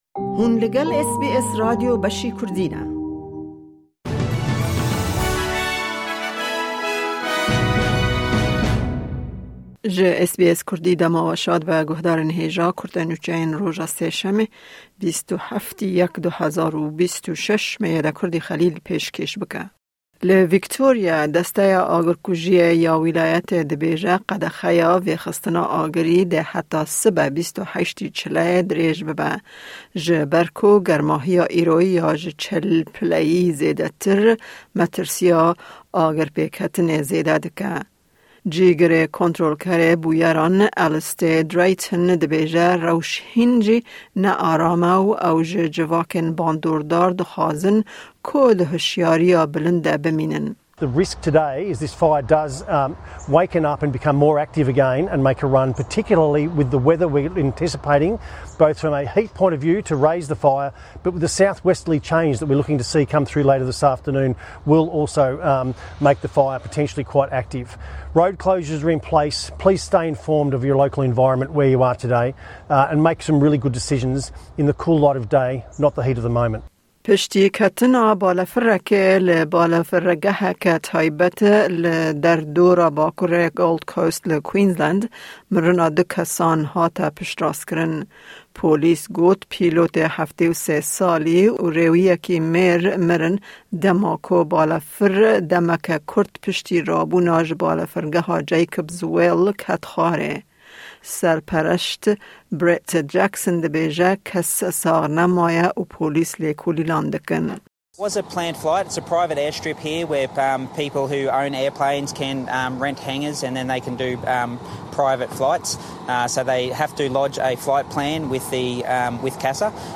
Nûçeyên roja Sêşemê 27/01/2026